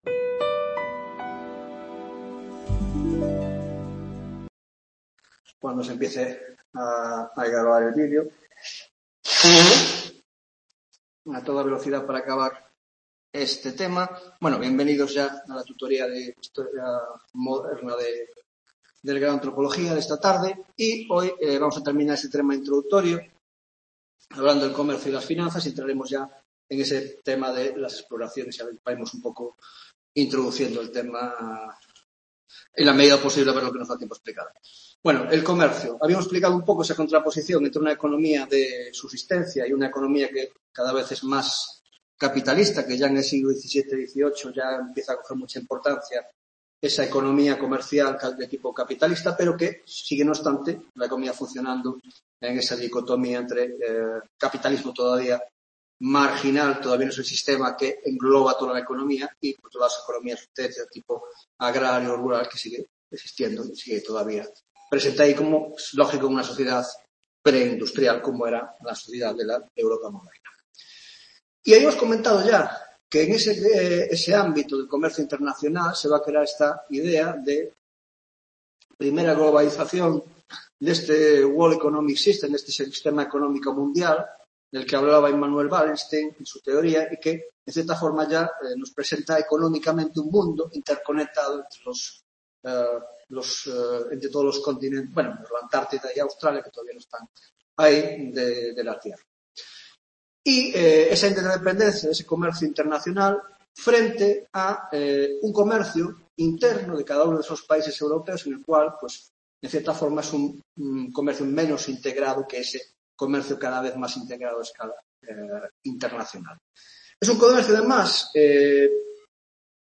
4ª Tutoria Historia Moderna (Grado de Antropolog+ia Social y Cultural)